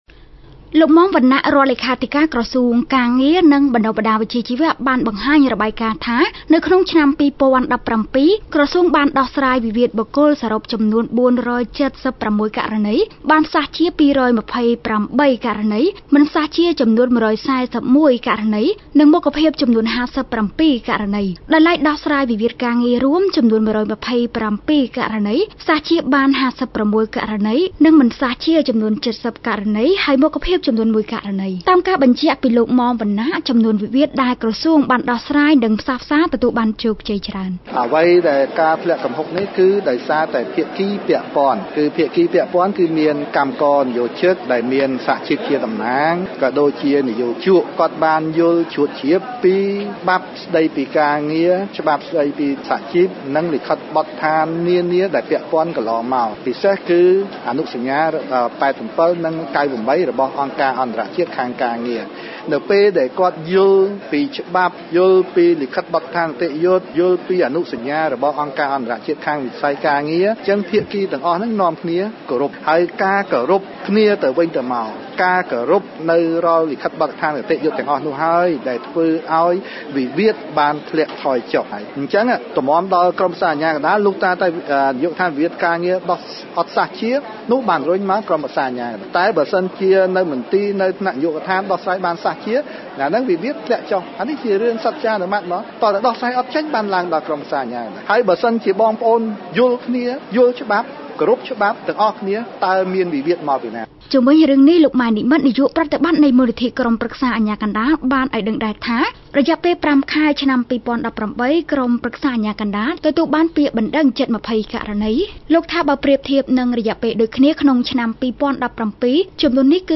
ជូនសេចក្ដីរាយការណ៍!